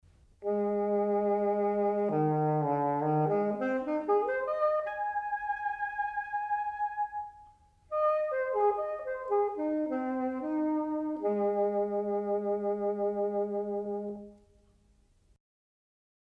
saxofono
Strumento musicale a fiato ad ancia, il sassofono combina nella sua costituzione l'ancia semplice e il bocchino del clarinetto con un corpo metallico e la forma conica, in versione ampliata, del tubo dell'oboe.
La qualità timbrica va dal dolce, vellutato e morbido, al freddo e metallico.
suono del sax
sax_suono.mp3